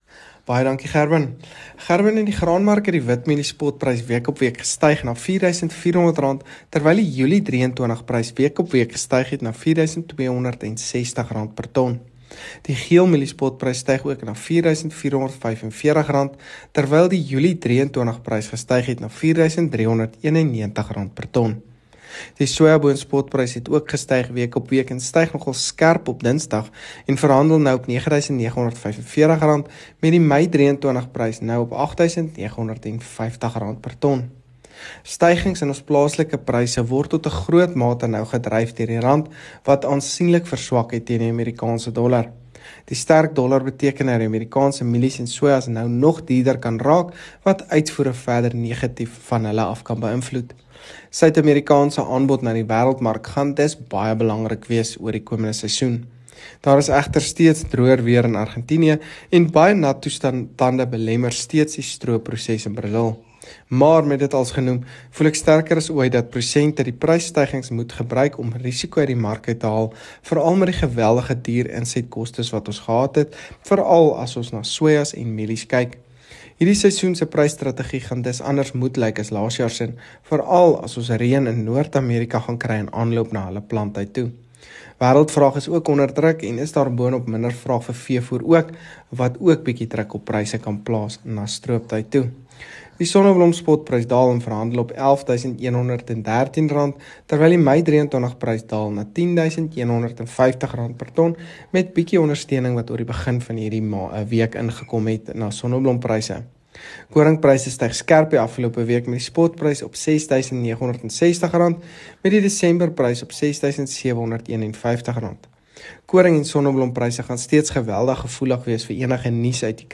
gesels oor kommoditeite